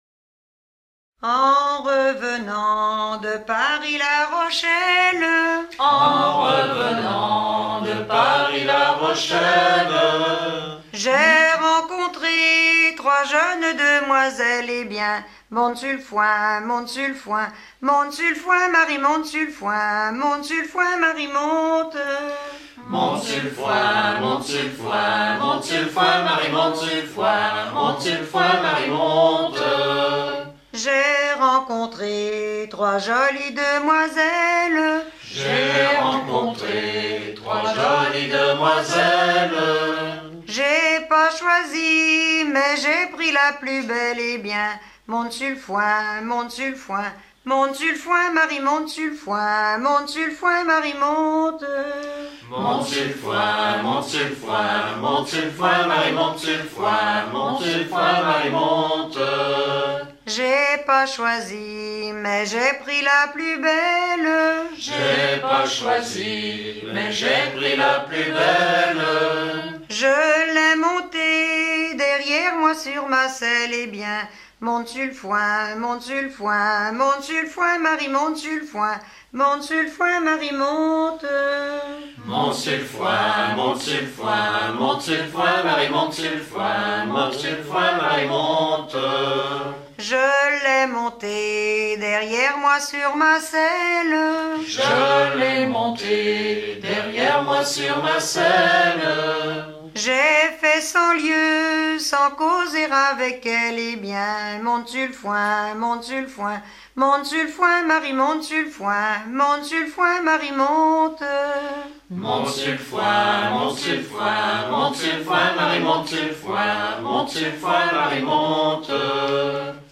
De Paris à La Rochelle Détails de l'archive Titre De Paris à La Rochelle Origine du titre : Editeur Note chantée à l'occasion du nettoyage et de la préparation d'une grange-étable pour le banquet de noce.
Genre laisse